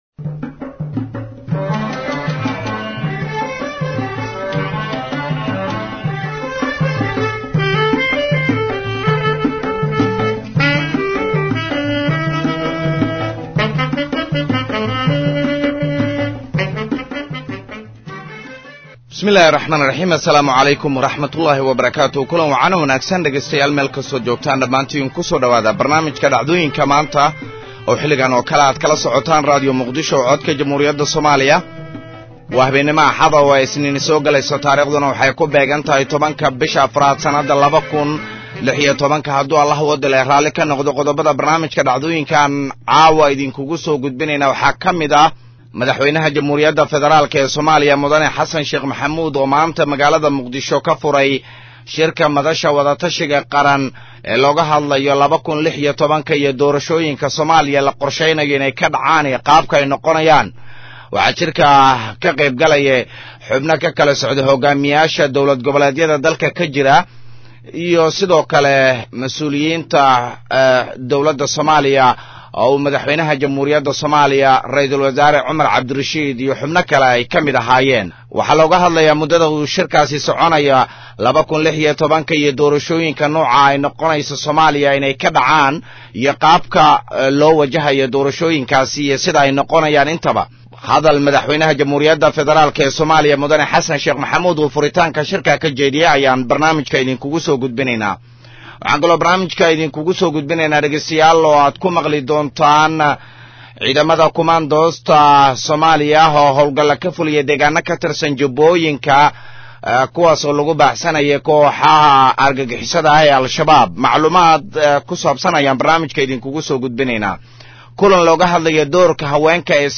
Barnaamijka waxaa diirada lagu saaraa raad raaca ama falanqeynta dhacdooyinka maalintaas taagan, kuwa ugu muhiimsan, waxaana uu xambaarsan yahay macluumaad u badan Wareysiyo.